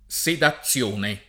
sedazione [ S eda ZZL1 ne ] s. f.